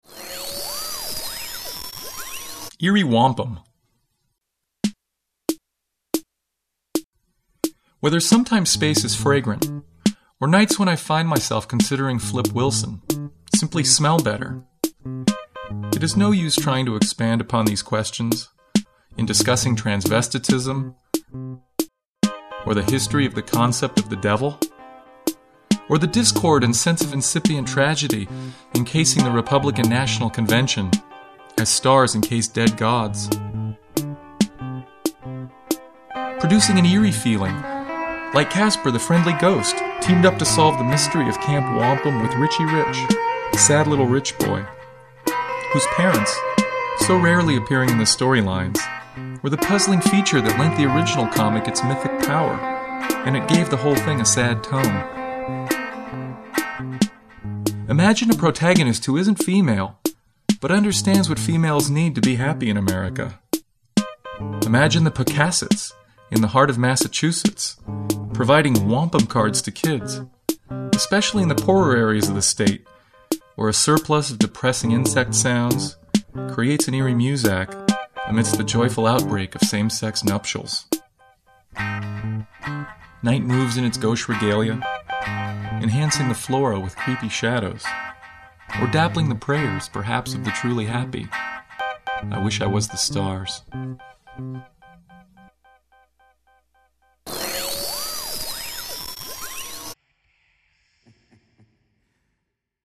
Eerie Wampum is a powerful, evocative poem, set to swank, swinging guitar. In short, it’s just the sort of headphone-friendly sonic brain transfusion today’s hep cats demand.